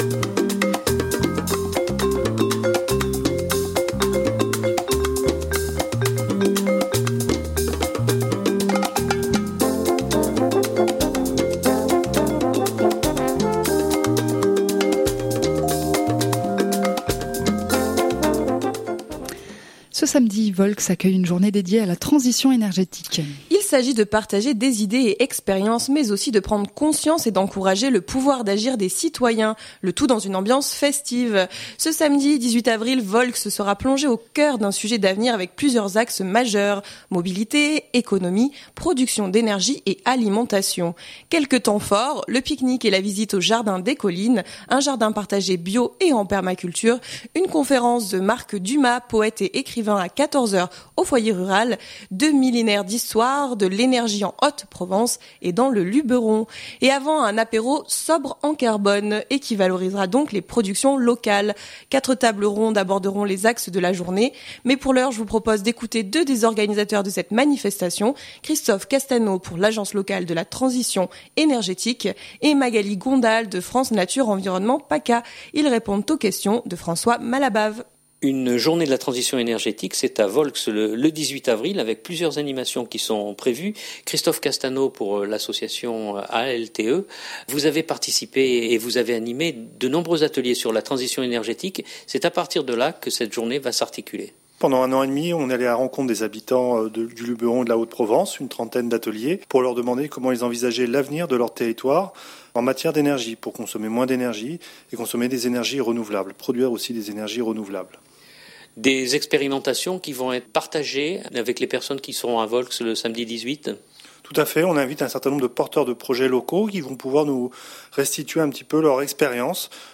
Je vous propose d’écouter deux des organisateurs de cette manifestation